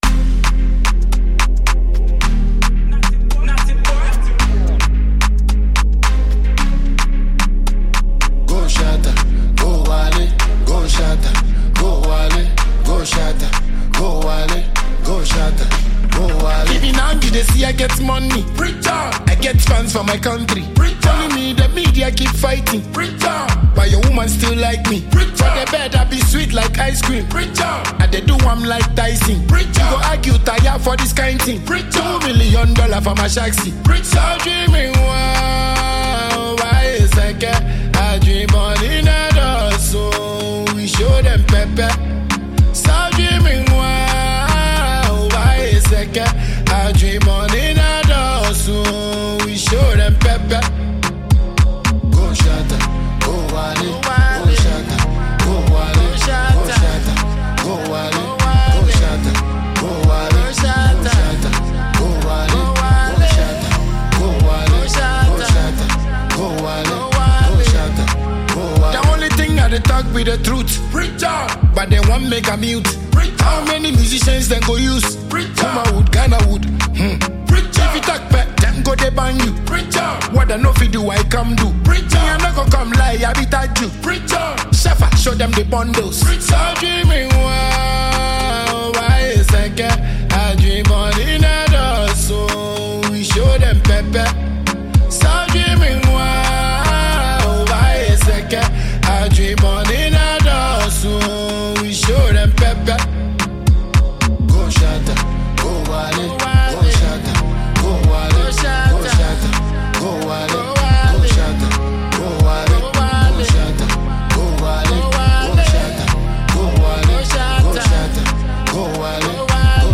the African Dancehall King from Ghana
This is a banger all day.